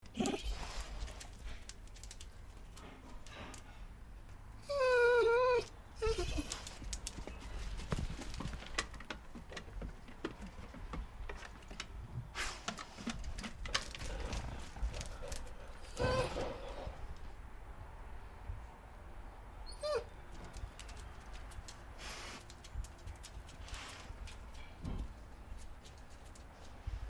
Dog Whine 2 Botón de Sonido